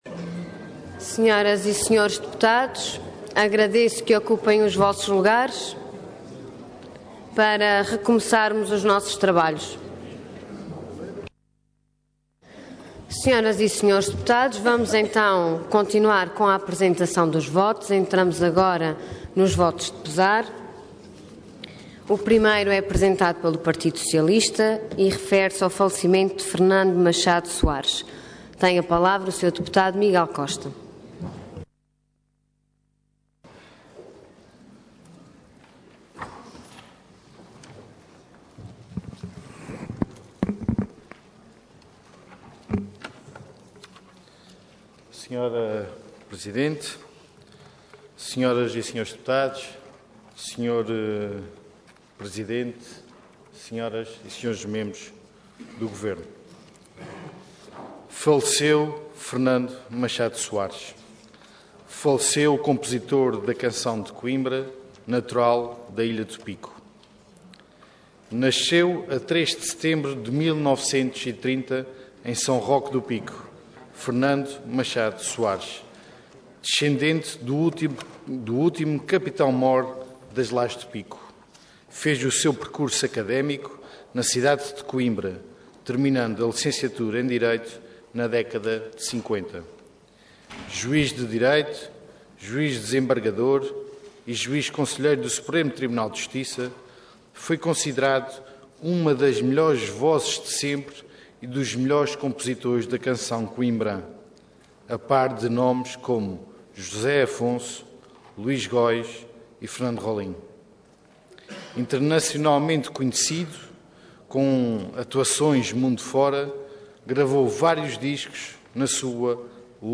Website da Assembleia Legislativa da Região Autónoma dos Açores
Detalhe de vídeo 14 de janeiro de 2015 Download áudio Download vídeo Processo X Legislatura Falecimento de Fernando Machado Soares Intervenção Voto de Pesar Orador Miguel Costa Cargo Deputado Entidade PS